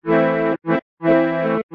Sample sounds, mostly quite short